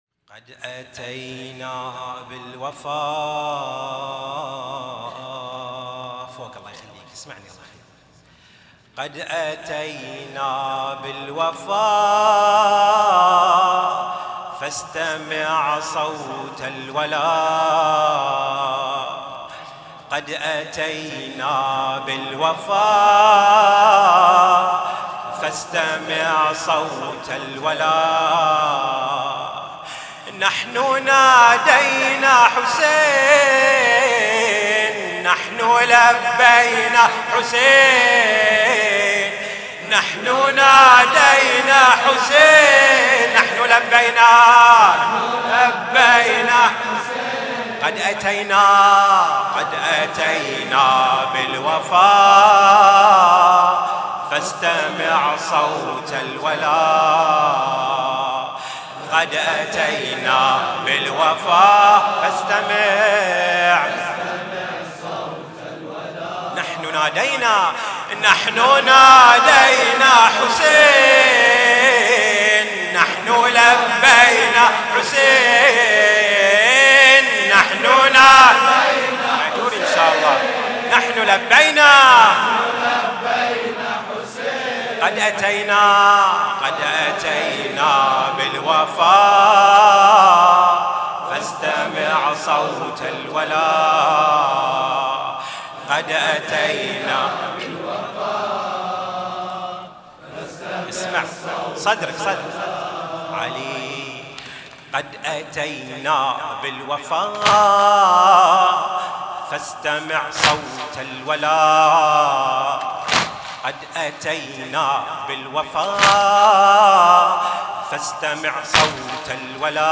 لطمية الليلـ 10 ـة الجزء (02)